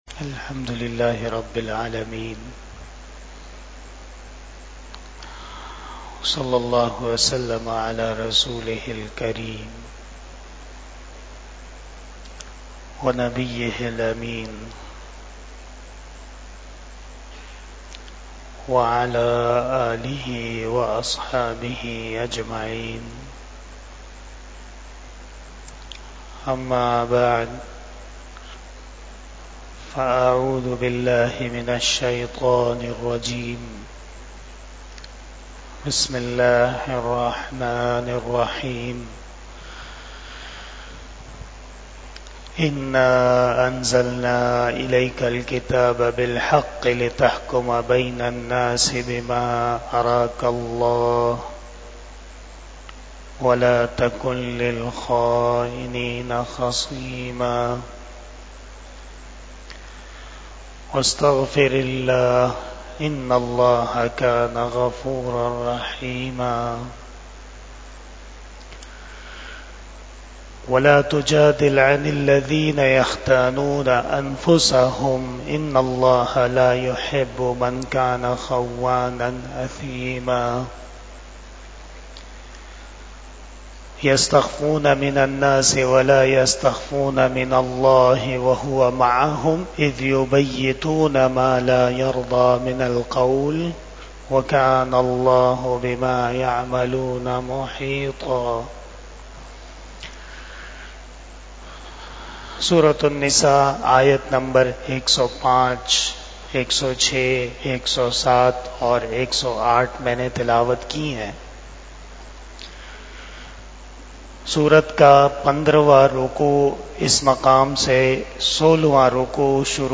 31 Shab E Jummah Bayan 19 September 2024 (15 Rabi ul Awwal 1446 HJ)